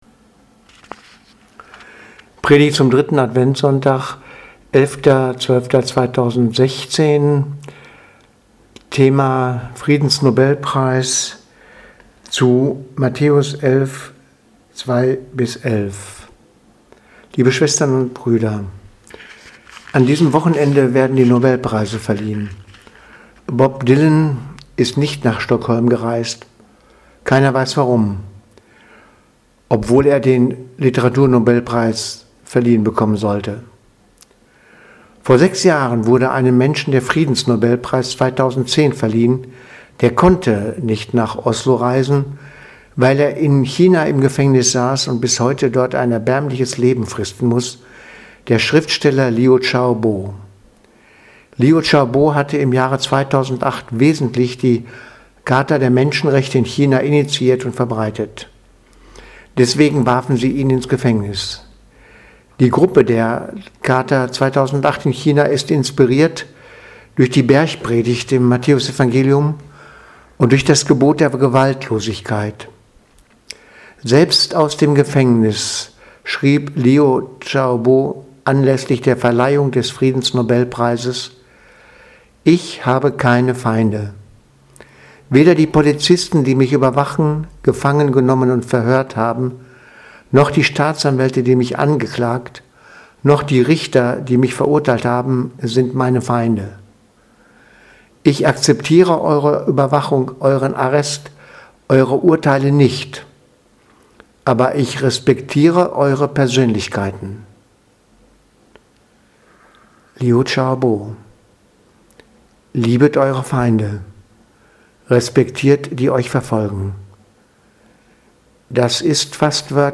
Predigt vom 11.12.2016 – Friedensnobelpreis
Predigt zum 3. Advent, 2016-12-11 (Bür 11.00)